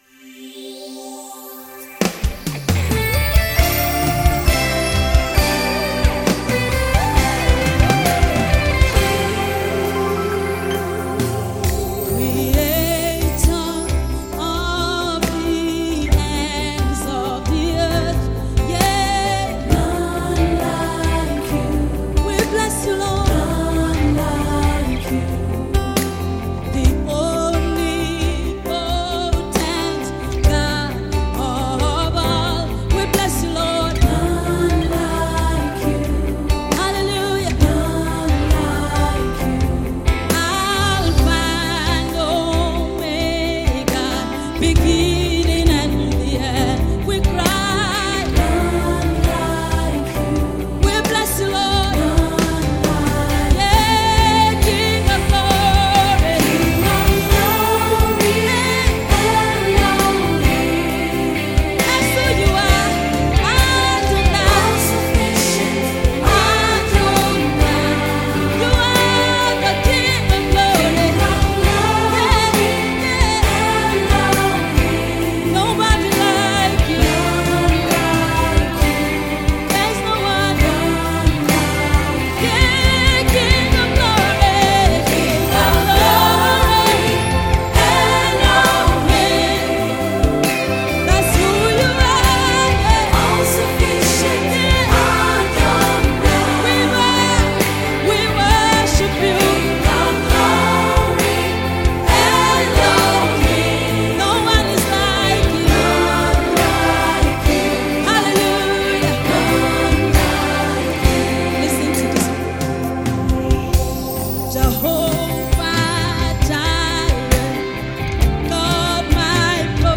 songs of worship
recorded live at greenland sound factory